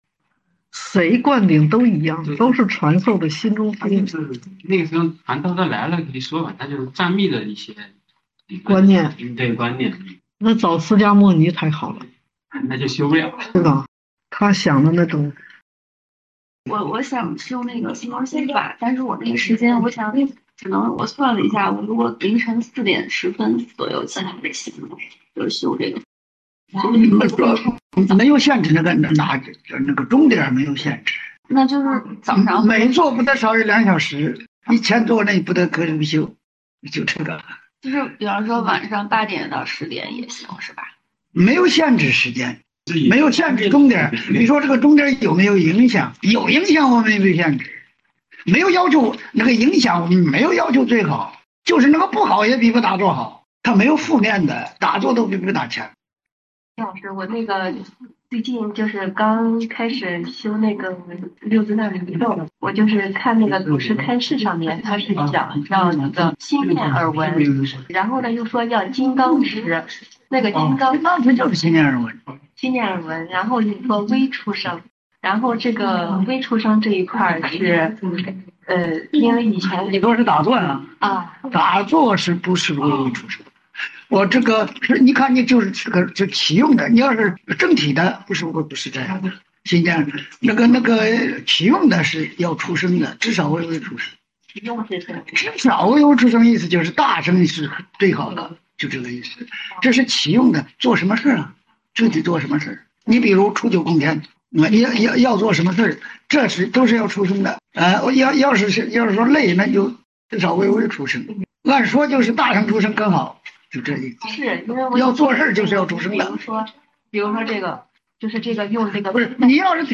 现场问答 - 心听音频 - 锵锵茶馆